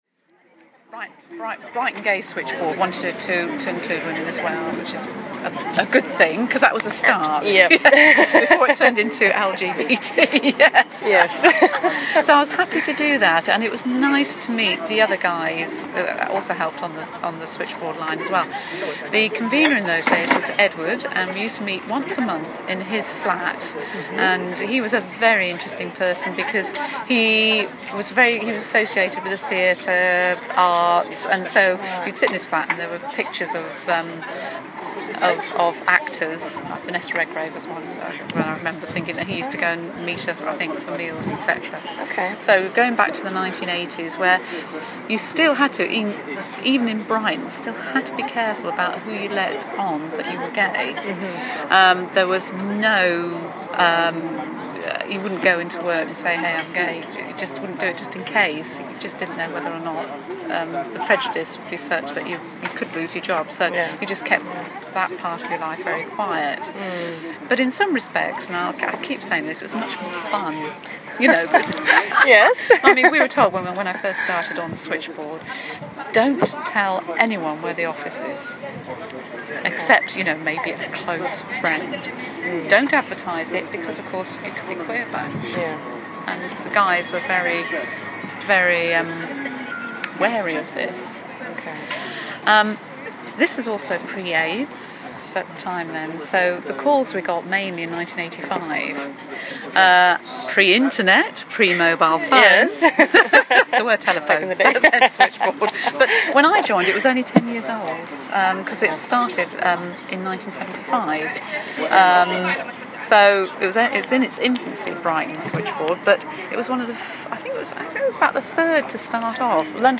A collection of over 100 recordings, documenting queer life in Brighton.
Oral History